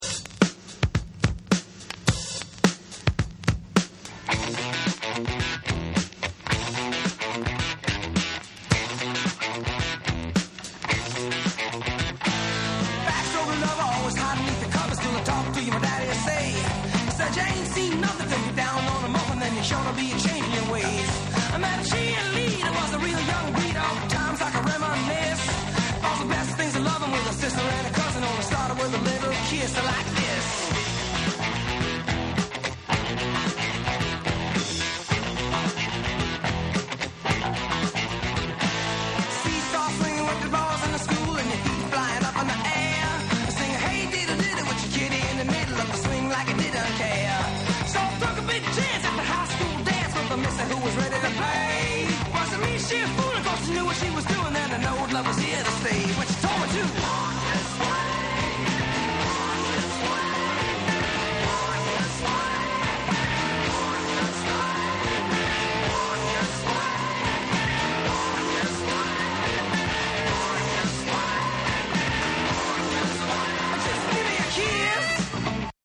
泣く子も黙るブレイクビーツ・クラシック
SOUL & FUNK & JAZZ & etc